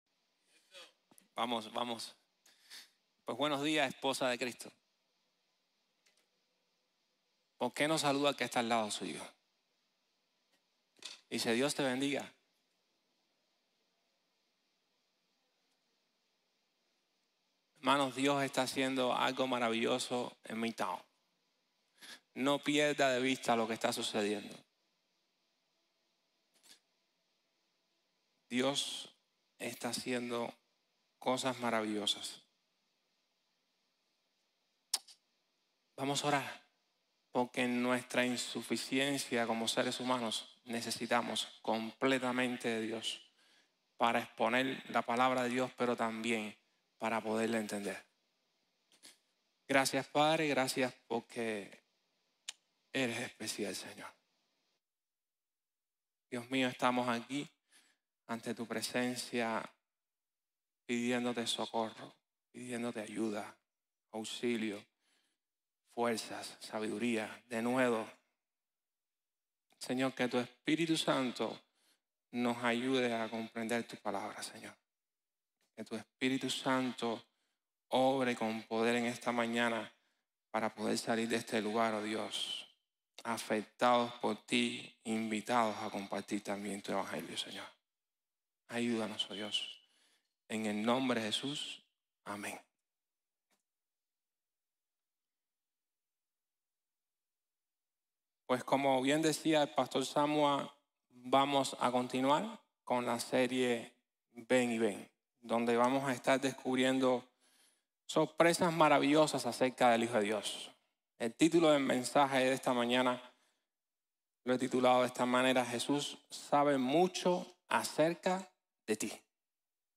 Prepárese para venir y ver | Sermón | Iglesia Bíblica de la Gracia